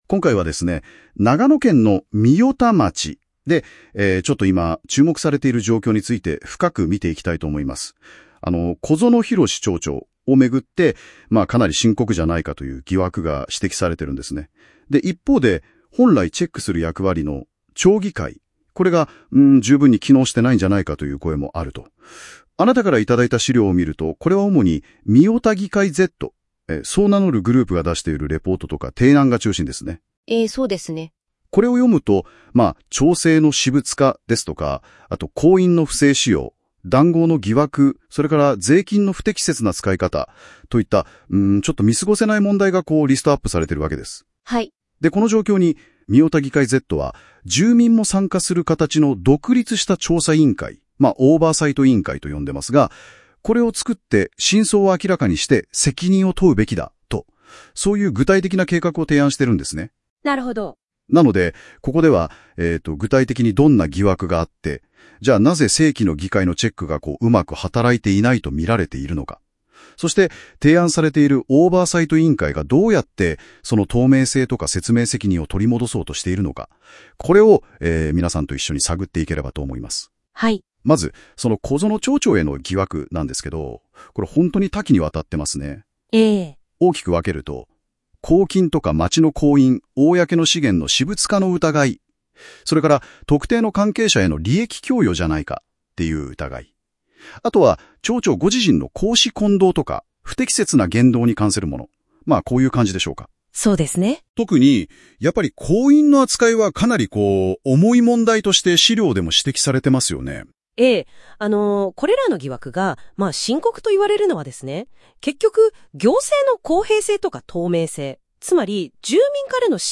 音声解説
AI生成なので固有名詞などの誤読がありますがご了承ください。